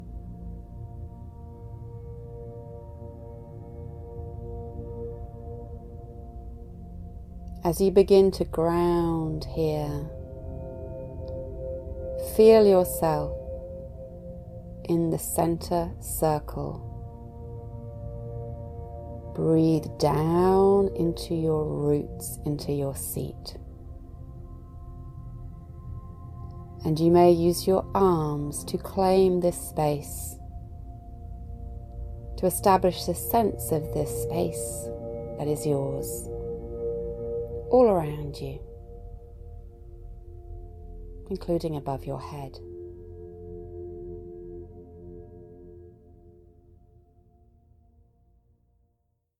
The album contains 19 tracks and 4 hours of recordings including guided meditations, instructional material and “re-sets”.